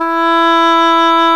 Index of /90_sSampleCDs/Roland LCDP04 Orchestral Winds/CMB_Wind Sects 1/CMB_Wind Sect 7
WND ENGHRN0B.wav